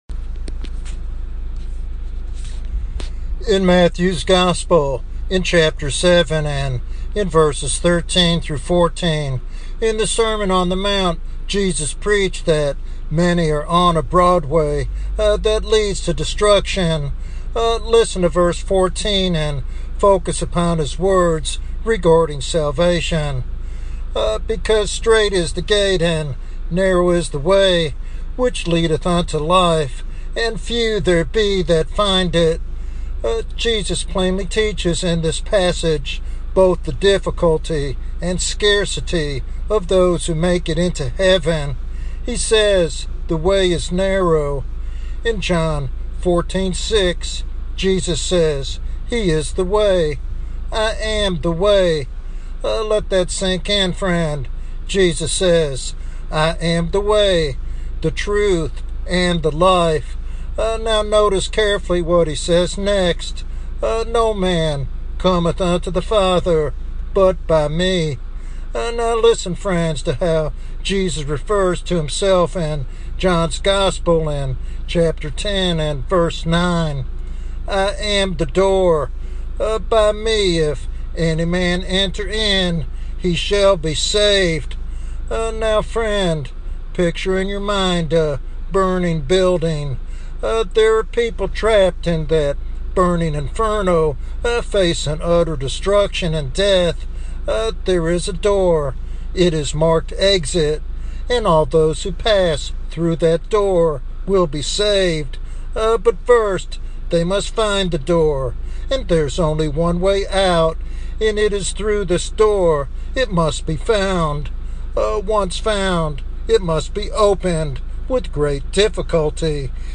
This evangelistic sermon calls for urgent decision-making in the face of eternal consequences.
Sermon Outline